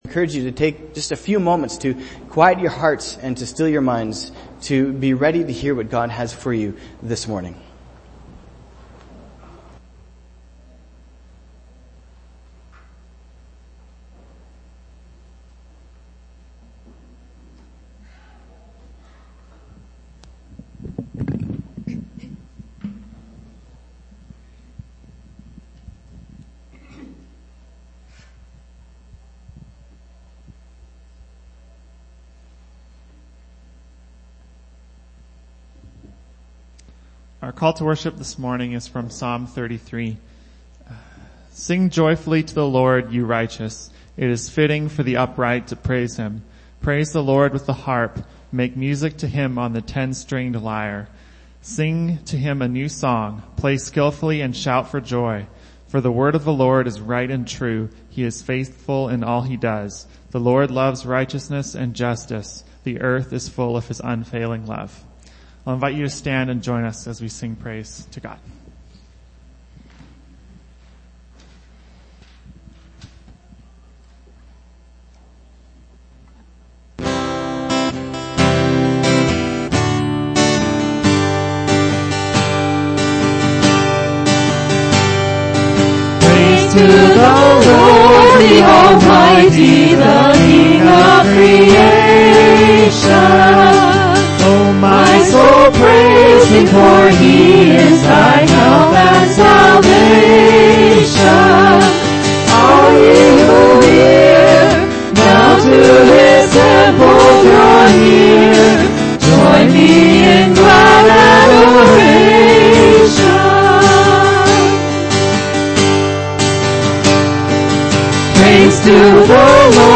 Argyle Road Baptist Church